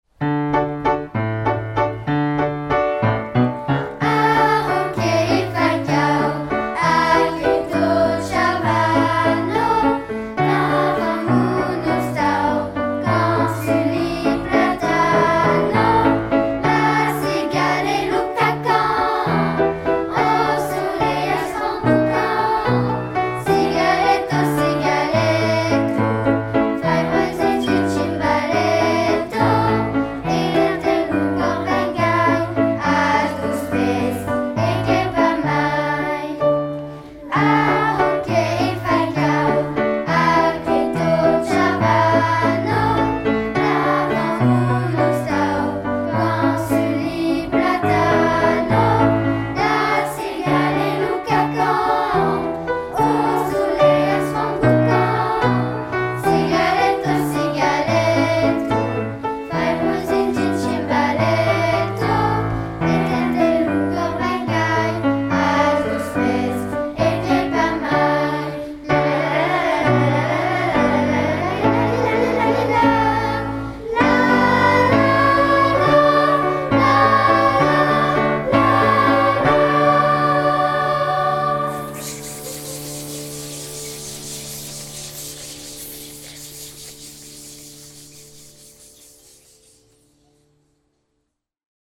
La-cigalo_J-H.FABRE_Eleves-6eCHAM-Vaison.mp3